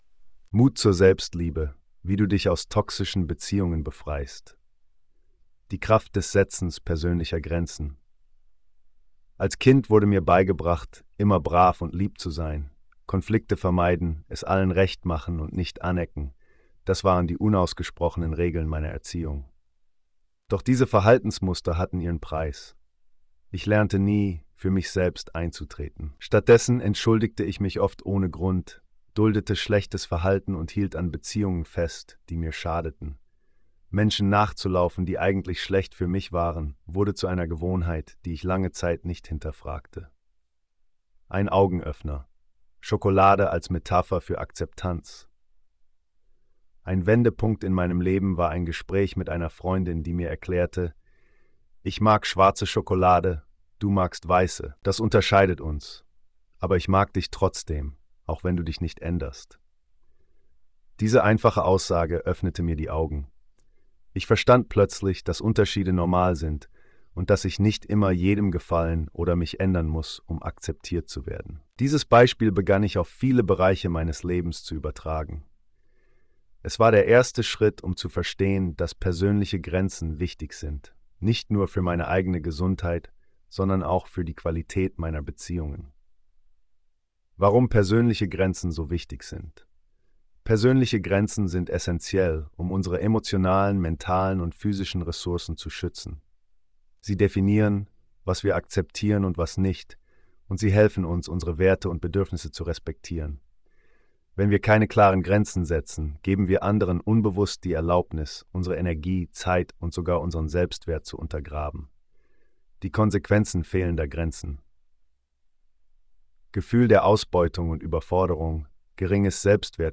Hör dir die Audio-Version dieses Artikels an (generiert von KI).